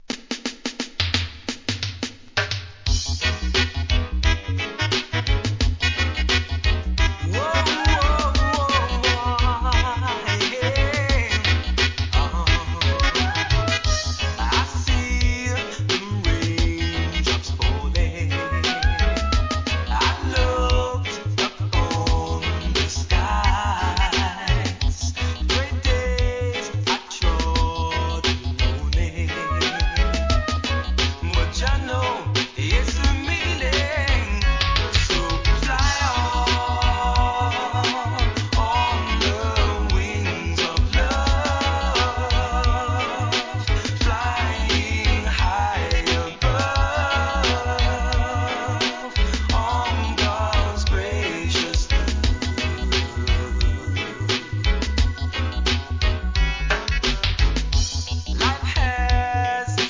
REGGAE
NICE VOCAL!!